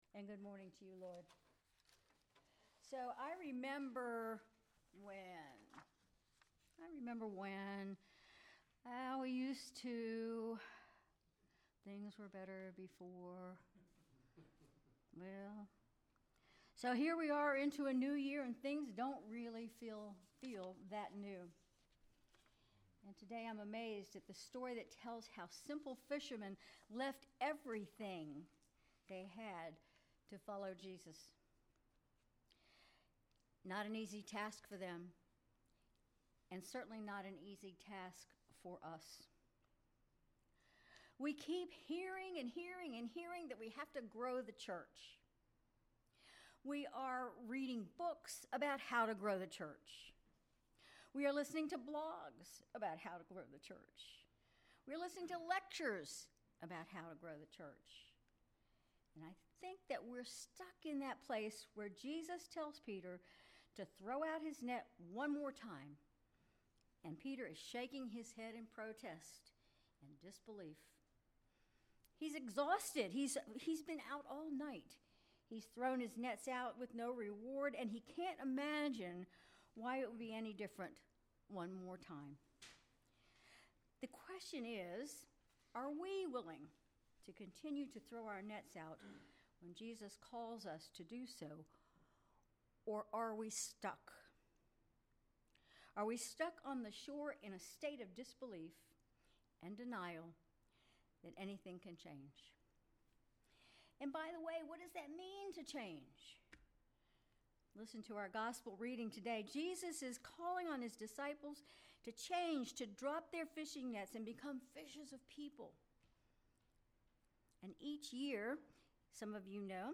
Sermon February 9, 2025
Sermon_February_9_2025.mp3